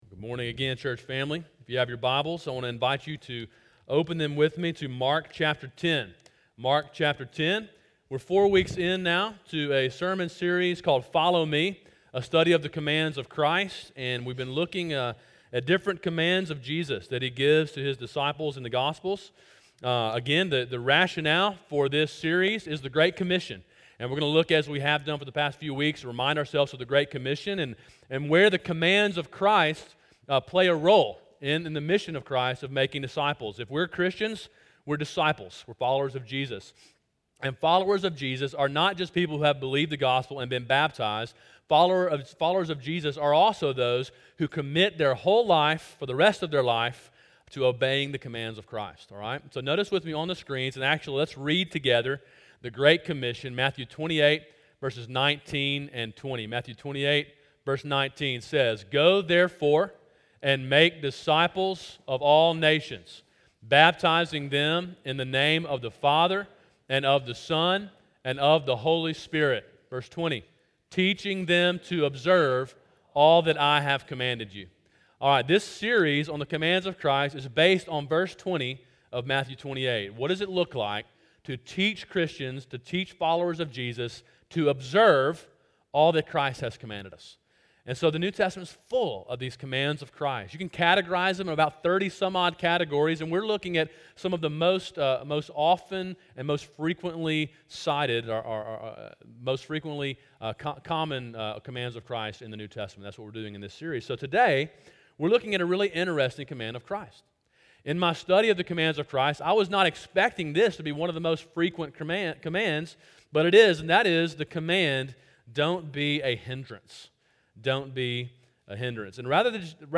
Sermon: “Don’t Be a Hindrance” (Mark 10:13-16, Mark 9:38-41, Matthew 23:1-4) – Calvary Baptist Church